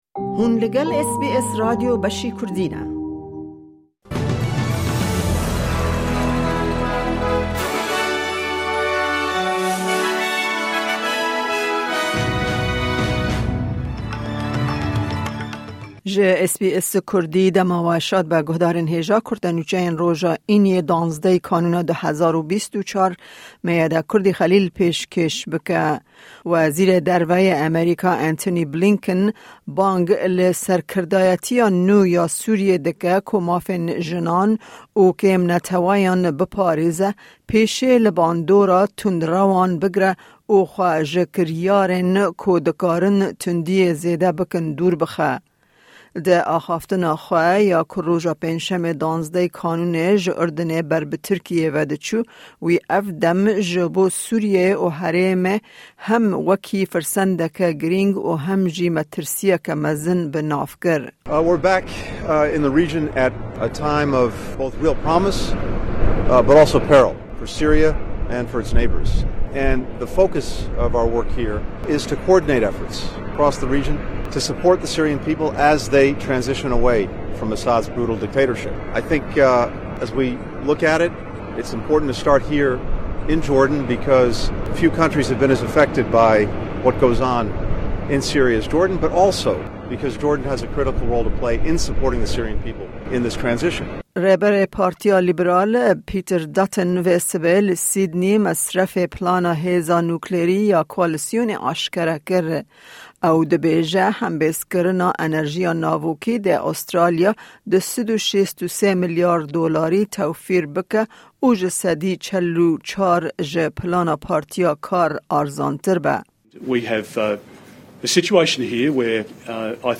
Kurte Nûçeyên roja Înî 13î Kanûna 2024